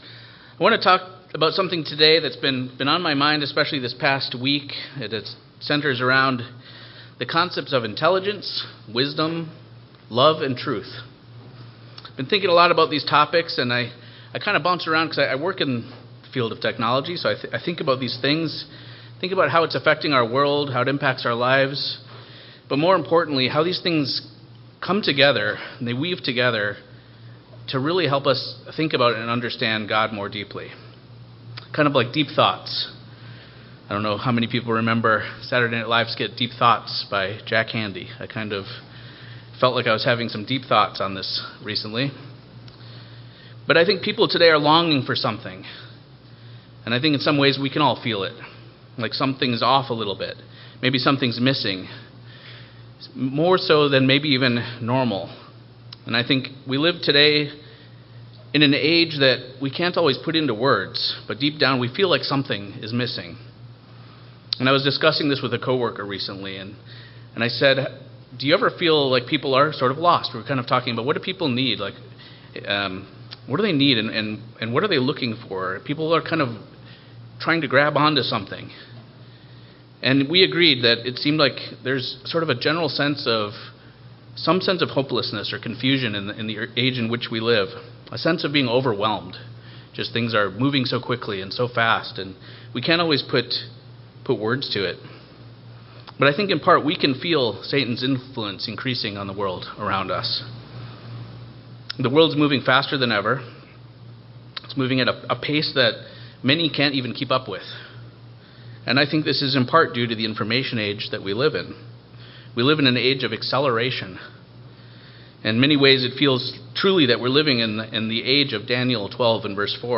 From AI “hallucinations” to the fire-hose of opinions and data, the sermon highlights how our age reflects Daniel 12:4: knowledge increasing, people running to and fro, yet fewer anchored in what truly matters. Using scriptures from Proverbs, Ecclesiastes, Corinthians, Philippians, and the words of Christ Himself, we learn that truth requires three elements working together: intelligence, wisdom, and love.